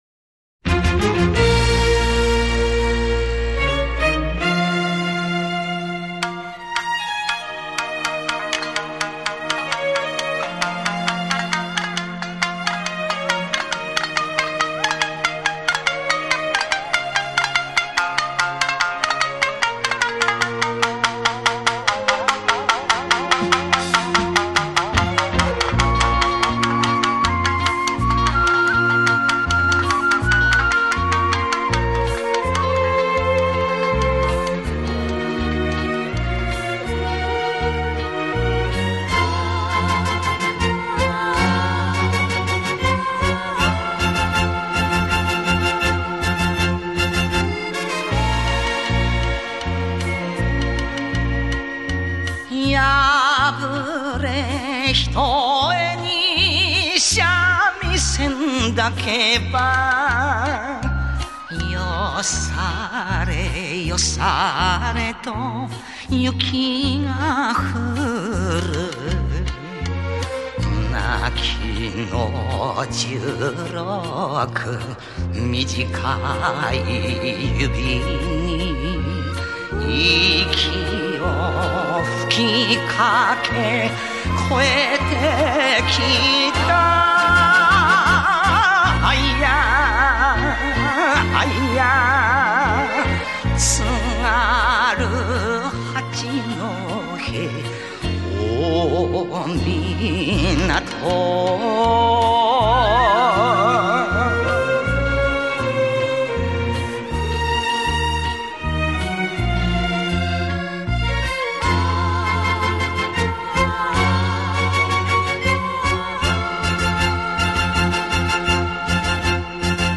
他将传统民间歌谣和现